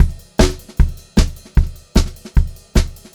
152SPCYMB2-L.wav